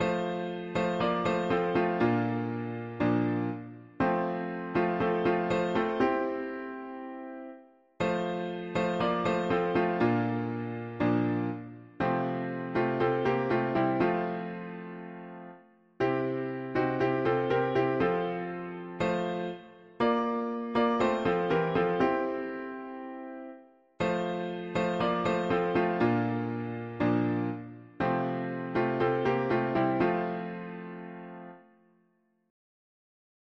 Oh, what peace we often forfei… english christian 4part chords